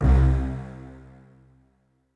STR HIT C1.wav